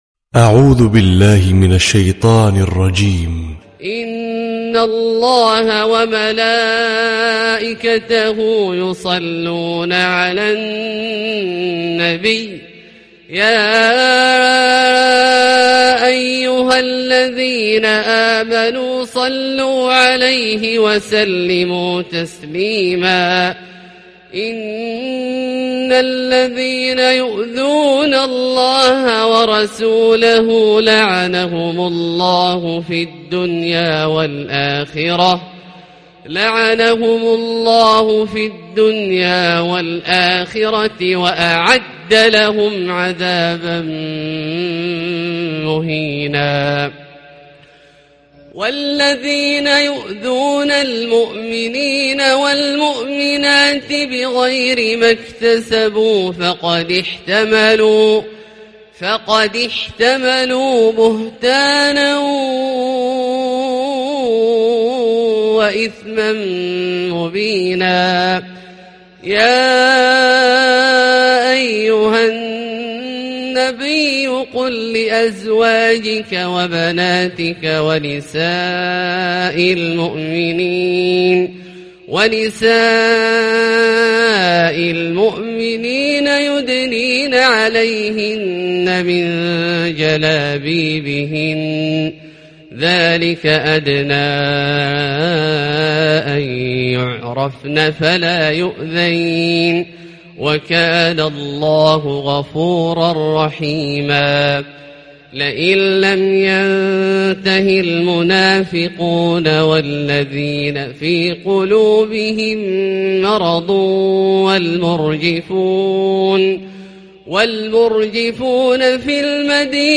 🕋🌻•تلاوة مسائية•🌻🕋
🎙 القارئ : عبدالله الجهني